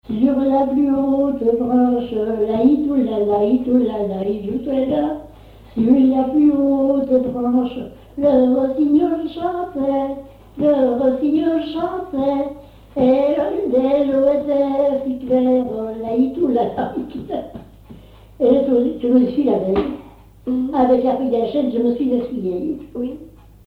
Localisation Cancale (Plus d'informations sur Wikipedia)
Genre laisse
Catégorie Pièce musicale inédite